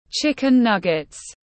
Gà viên chiên tiếng anh gọi là chicken nuggets, phiên âm tiếng anh đọc là / ˈtʃɪkɪn ˈnʌɡɪt/
Chicken nuggets / ˈtʃɪkɪn ˈnʌɡɪt/